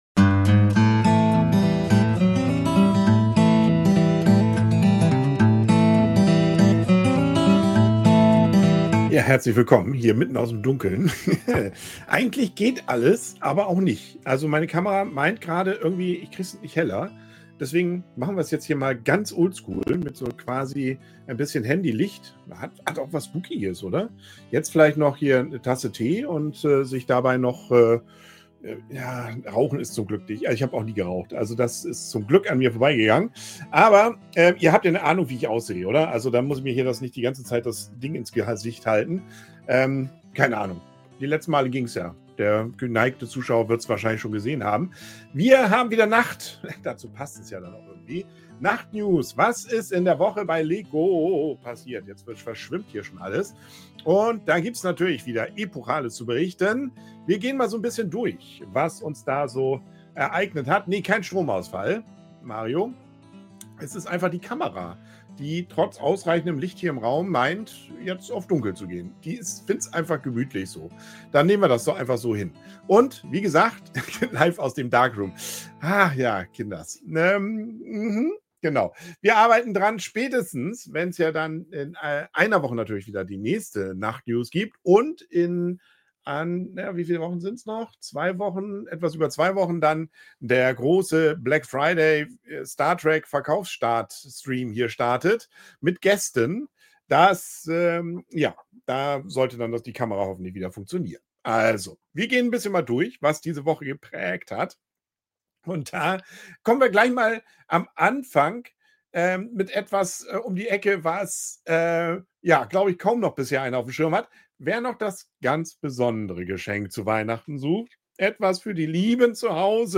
Live: Klemmbausteinlyrik LEGO Nacht-News 10.11.2025